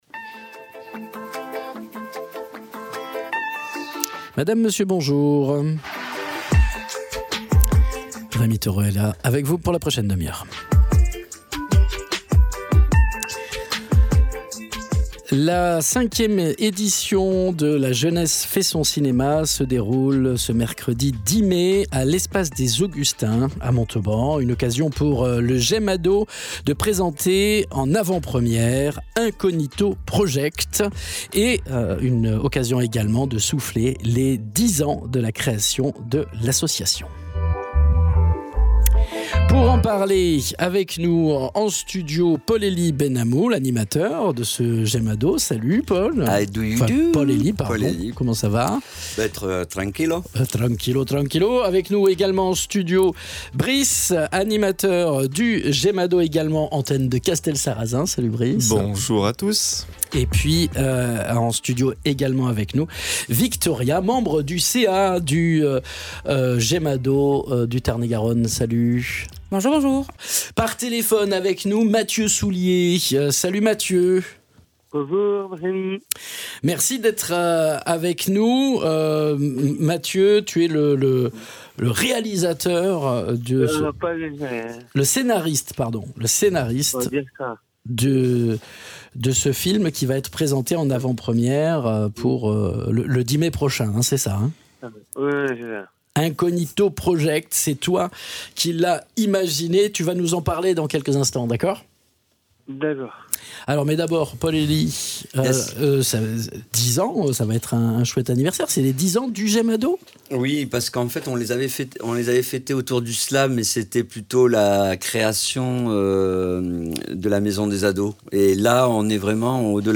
animateurs